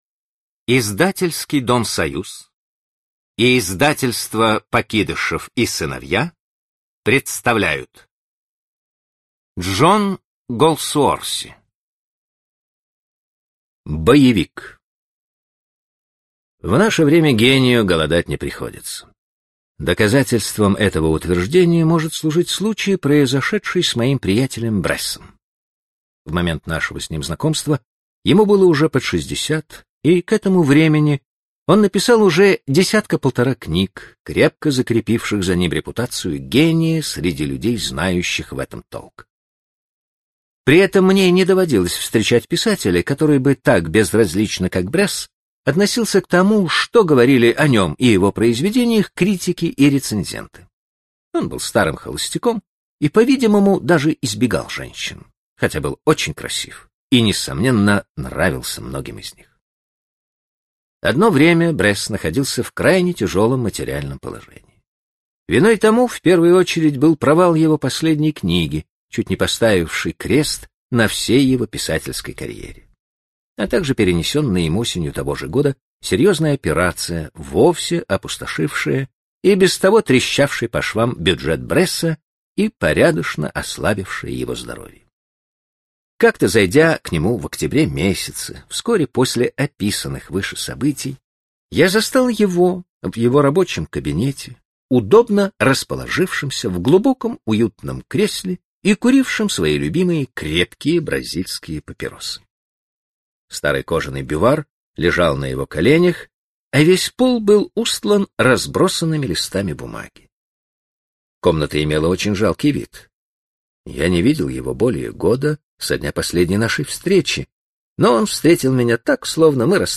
Аудиокнига Рваный башмак | Библиотека аудиокниг